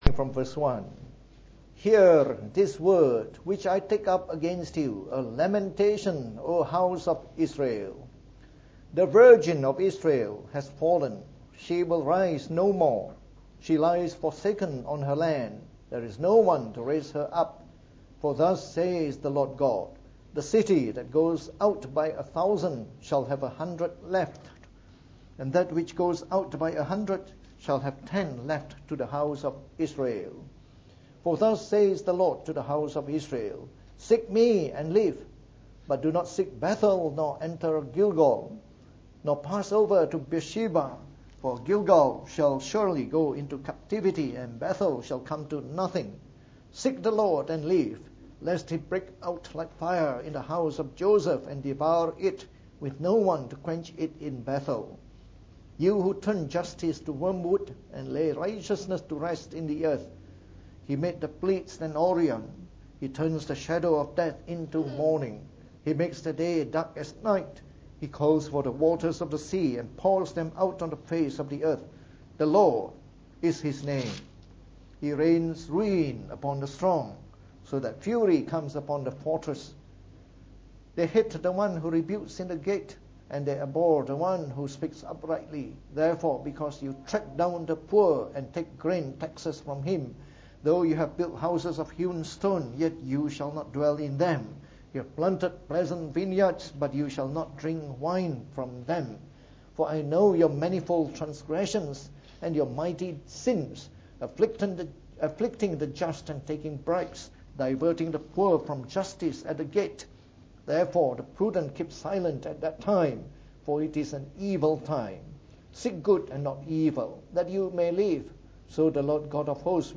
From our series on the Book of Amos delivered in the Morning Service.